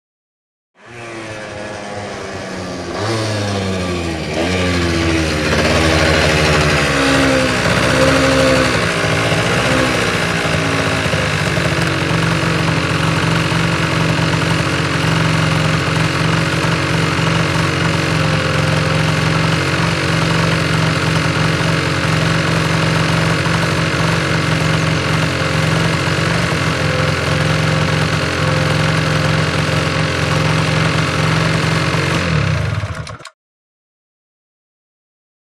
Moped; In / Stop; Moped Up, Stop, Tickover And Switch Off Tr07